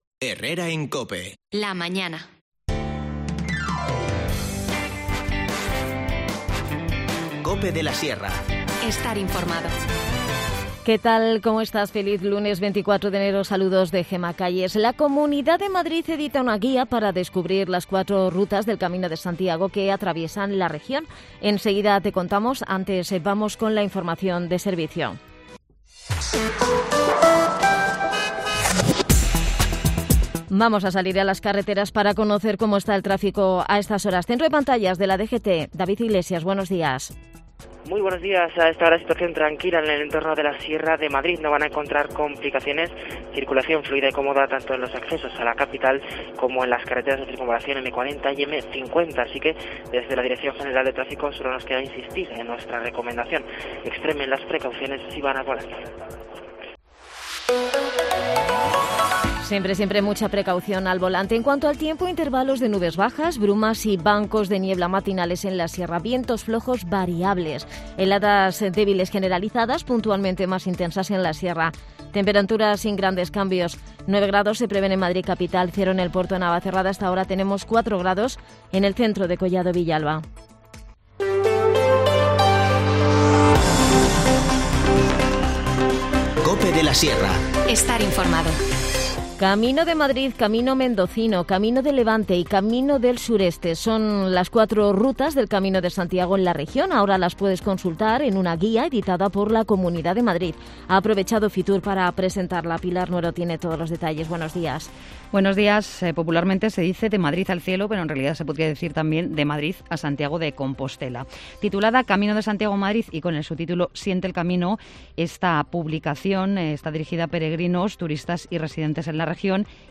Hablamos con Mercedes Nuño, concejal de Seguridad Cuidadana, que también se personó en el lugar para dar apoyo a los vecinos desalojados y ofrecerles alojo en el polideportivo municipal.